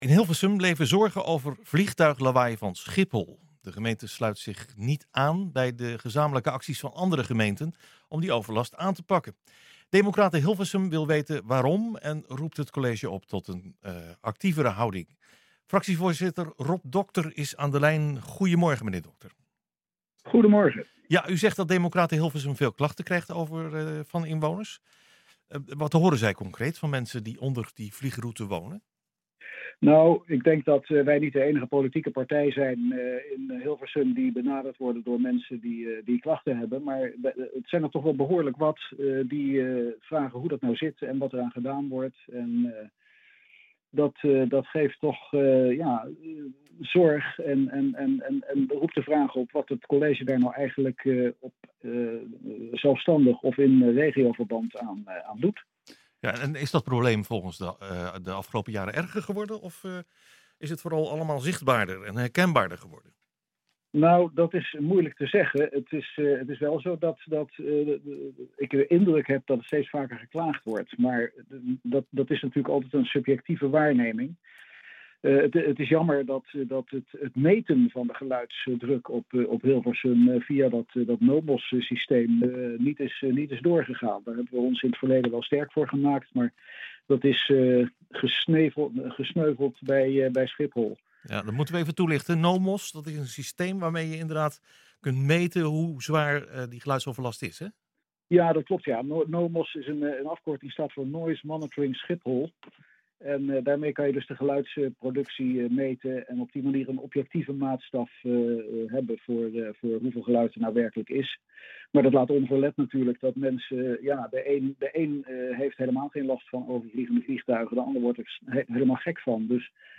Democraten Hilversum wil weten waarom en roept het college op tot een actievere houding. Fractievoorzitter Rob Docter is aan de telefoon.